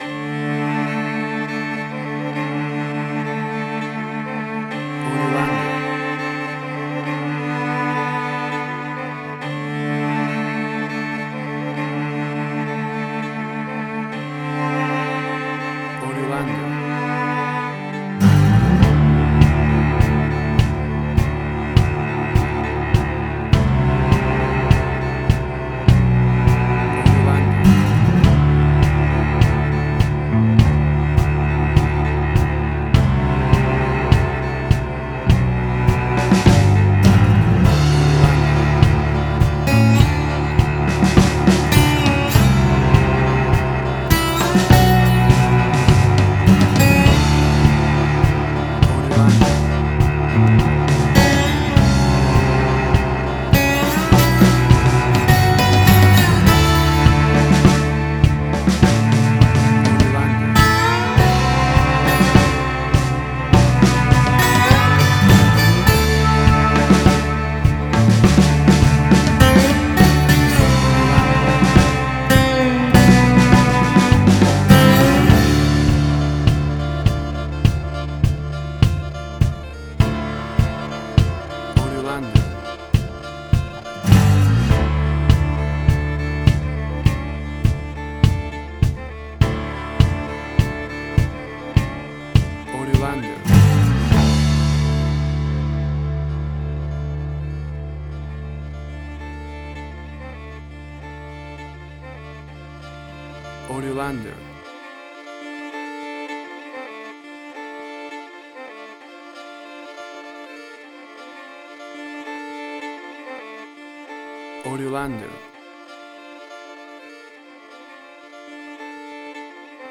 Swampy Blues_Americana.
Tempo (BPM): 102